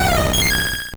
Cri de Mentali dans Pokémon Or et Argent.